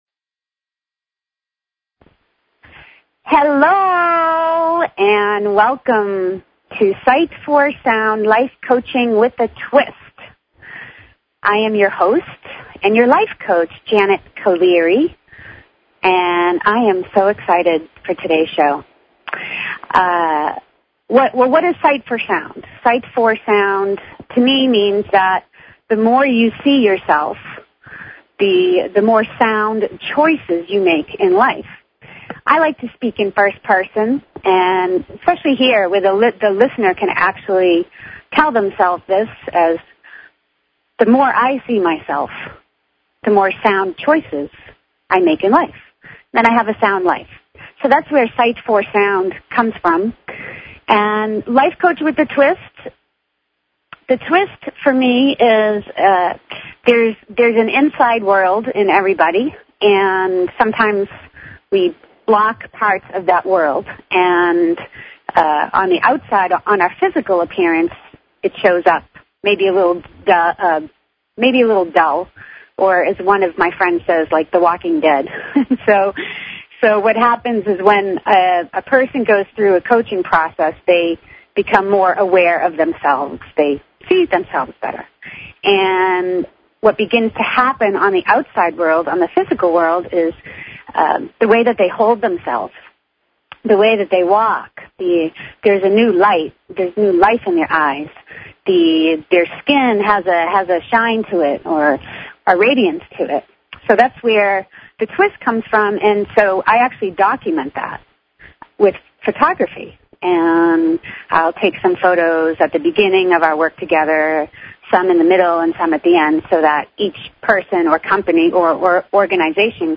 Talk Show Episode, Audio Podcast, Sight_for_Sound and Courtesy of BBS Radio on , show guests , about , categorized as
She encourages you to call in and ask questions or share thoughts!!!